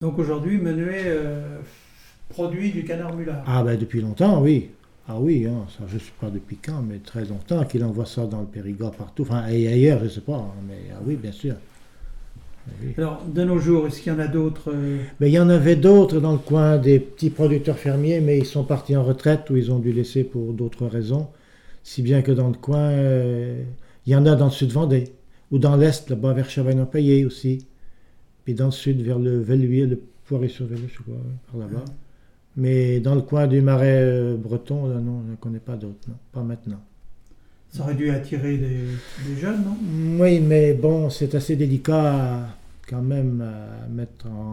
RADdO - La production de jeunes canards mûlards - Document n°232517 - Témoignage
Il provient de Saint-Gervais.